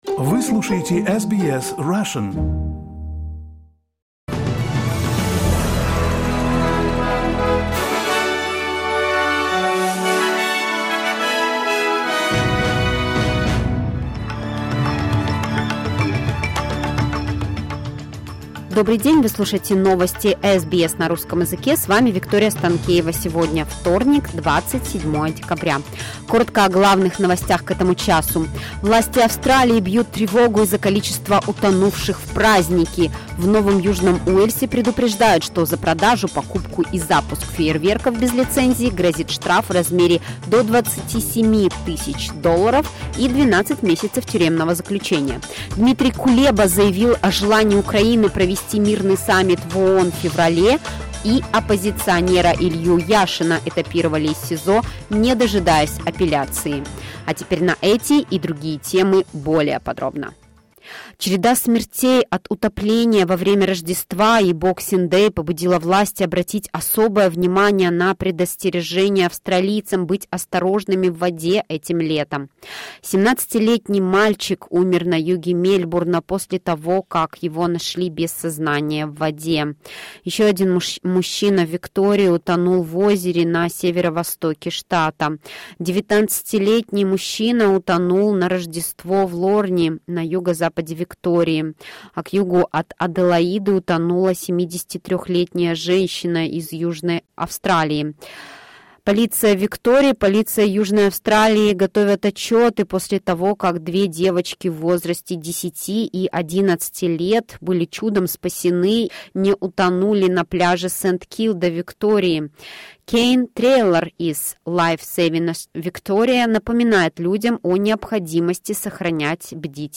SBS news in Russian — 27.12.2022